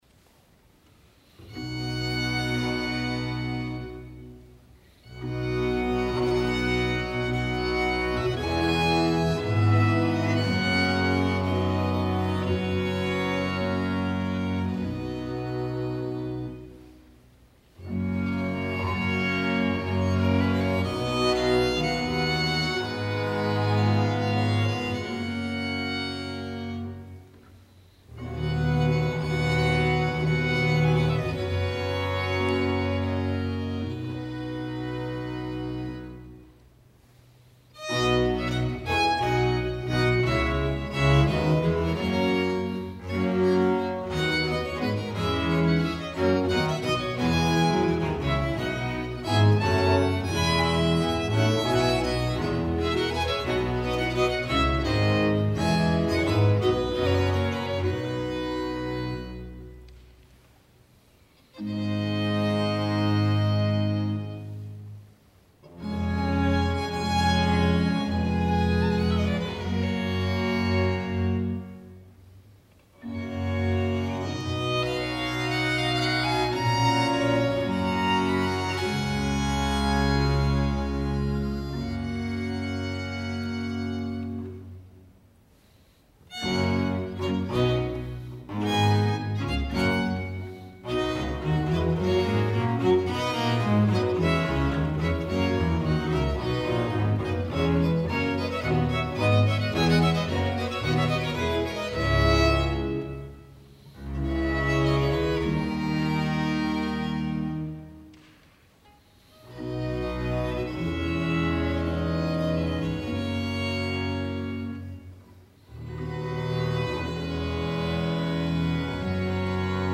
Sonata da Camera cioe Sinfonie 室内ソナタ すなわち シンフォニアより